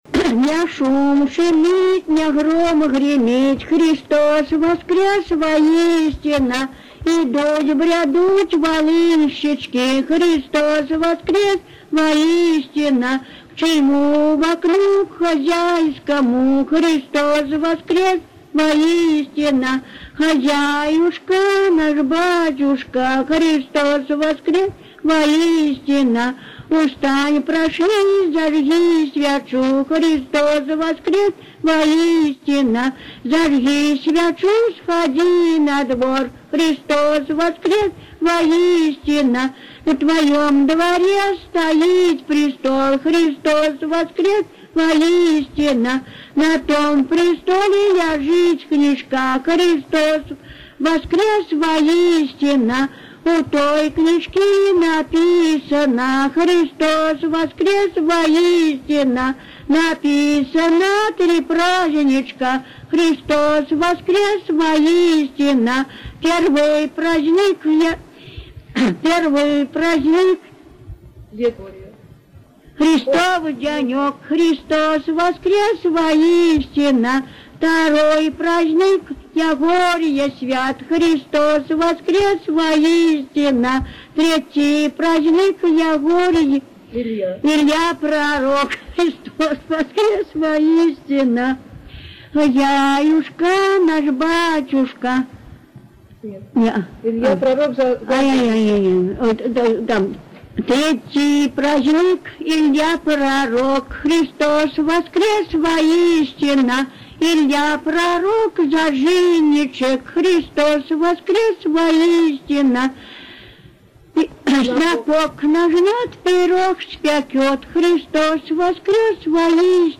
Волочебная песня.